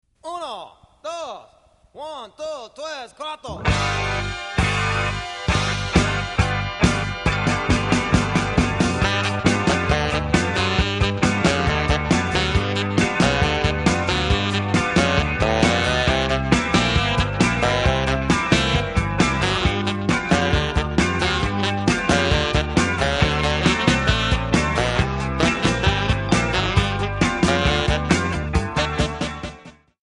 Backing track files: Pop (6706)